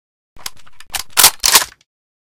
unjam.ogg